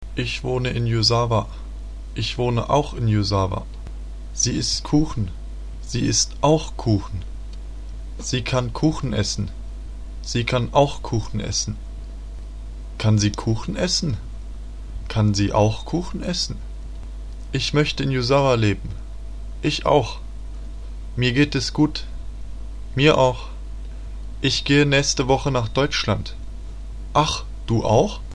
の単語の発音（.mp3)